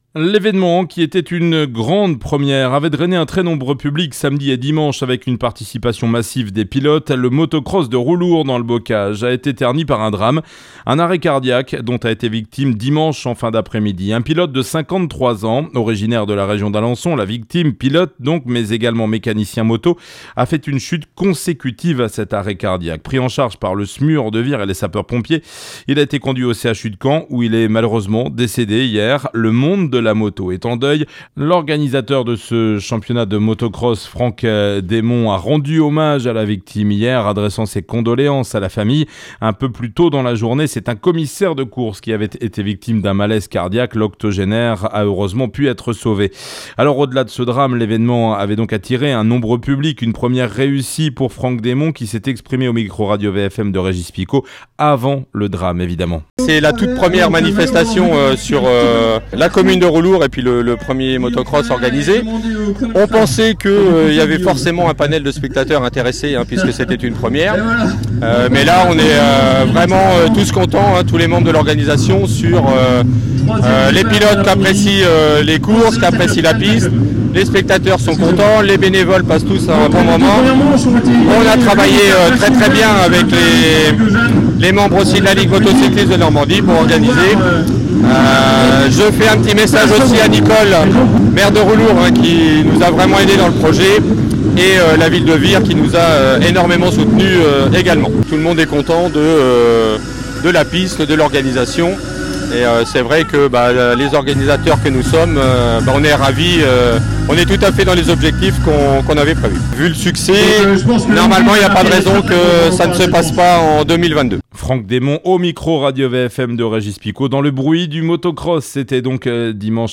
INFOS MARDI VIRE - 6 MINUTES -  - JOURNAL.mp3